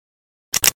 kiparis_unjam.ogg